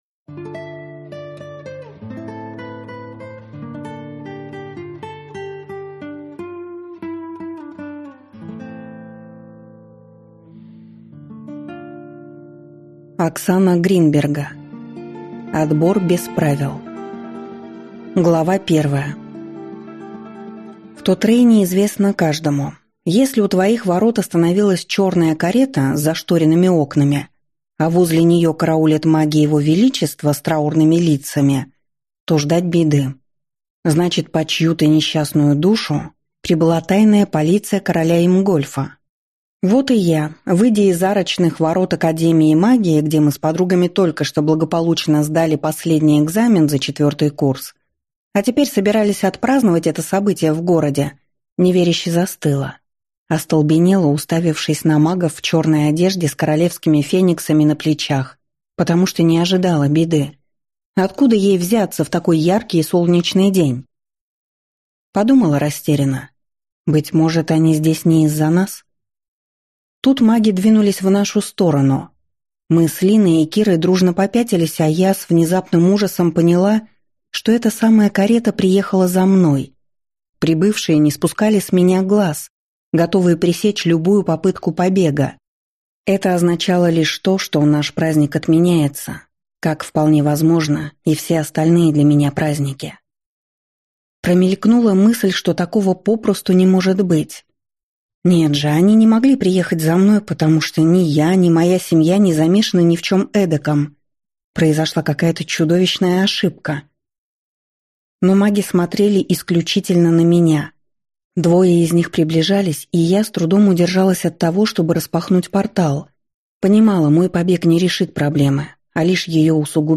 Аудиокнига Отбор без правил | Библиотека аудиокниг